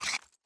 rpg7_load1.wav